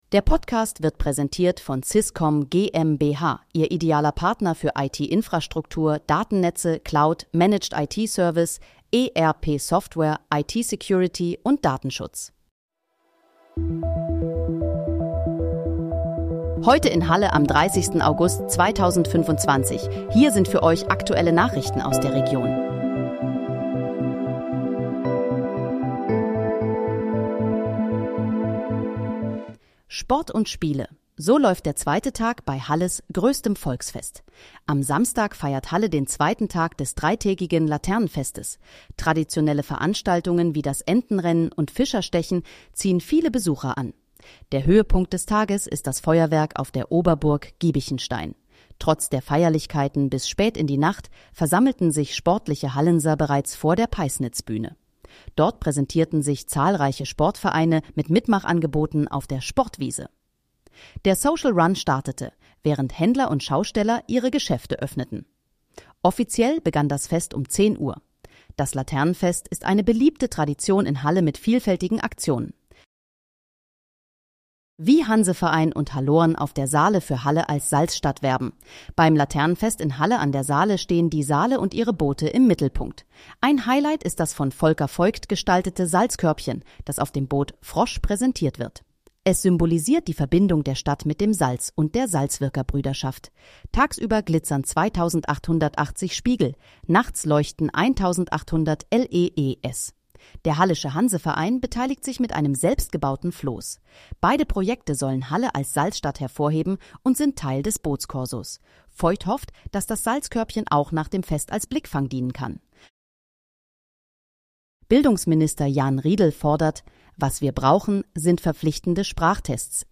Heute in, Halle: Aktuelle Nachrichten vom 30.08.2025, erstellt mit KI-Unterstützung
Nachrichten